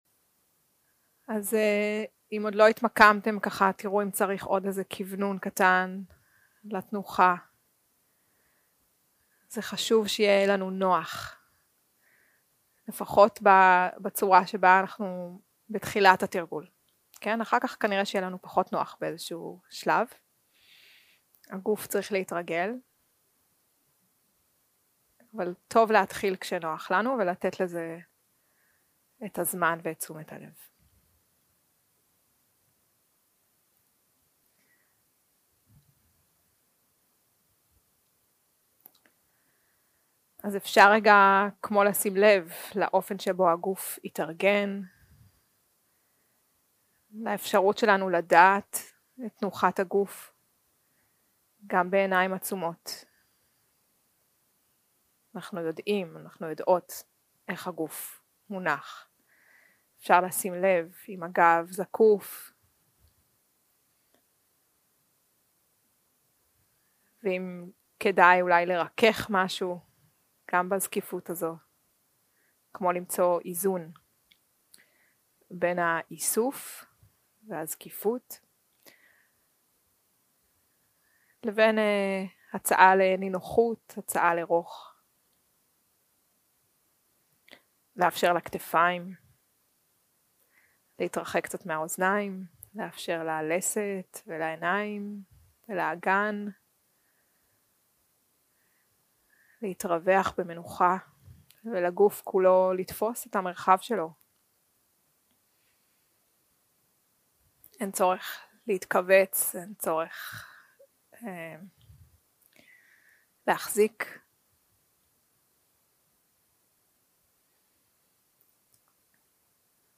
יום 2 – הקלטה 3 – צהריים – מדיטציה מונחית – מודעות לגוף.mp3
יום 2 – הקלטה 3 – צהריים – מדיטציה מונחית – מודעות לגוף.mp3 0:00 0:00 סוג ההקלטה: Dharma type: Guided meditation שפת ההקלטה: Dharma talk language: Hebrew